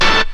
hitTTE68015stabhit-A.wav